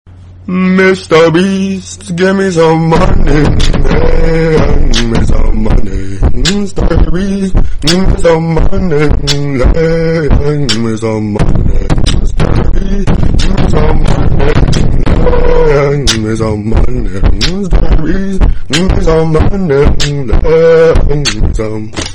mr beast give me sum money Meme Sound Effect